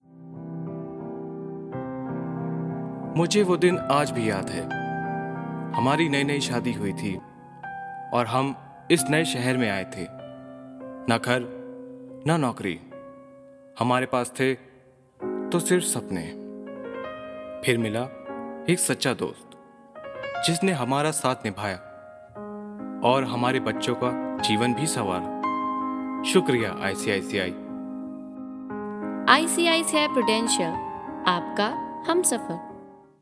Clear,young, mid range to low, friendly to authoritative voice. Good diction in both Hindi and English.
Sprechprobe: Werbung (Muttersprache):